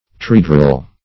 Meaning of triedral. triedral synonyms, pronunciation, spelling and more from Free Dictionary.
Search Result for " triedral" : The Collaborative International Dictionary of English v.0.48: Triedral \Tri*e"dral\, a. See Trihedral .